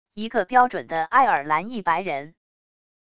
一个标准的爱尔兰裔白人wav音频